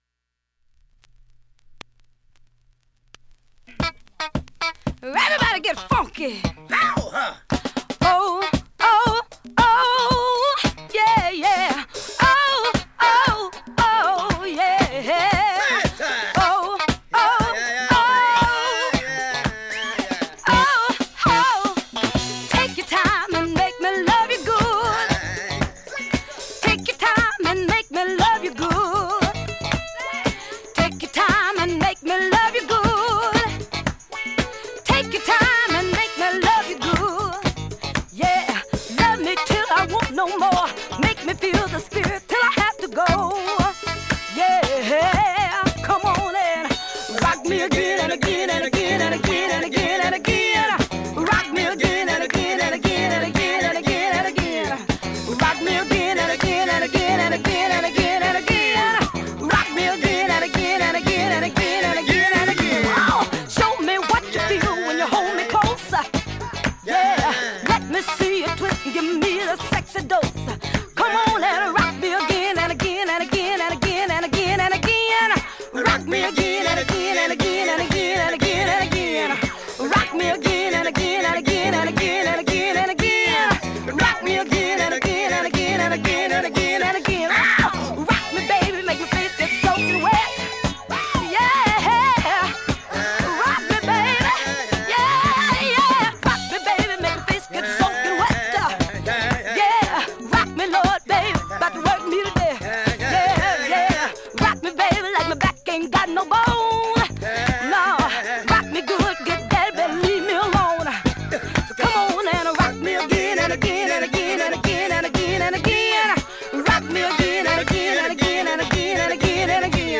◆USA盤 オリジナルPromo 7"Single 45 RPM
現物の試聴（両面すべて録音時間５分１８秒）できます。